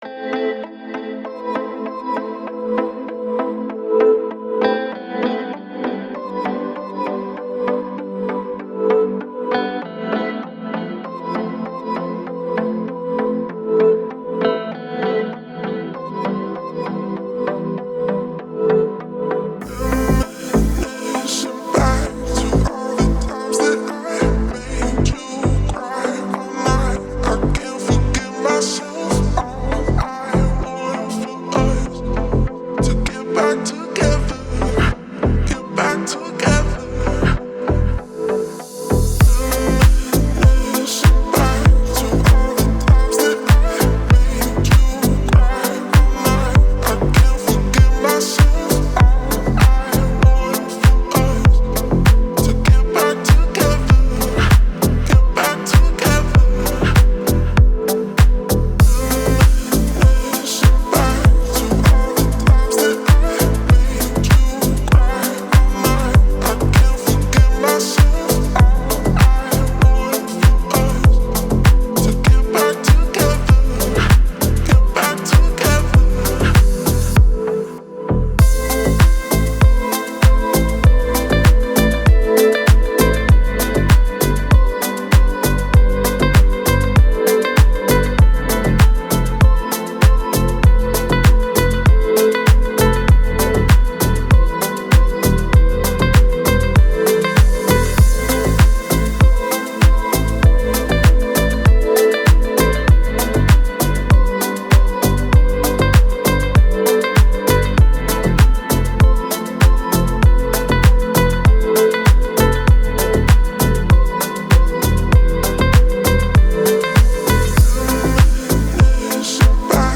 красивая музыка без слов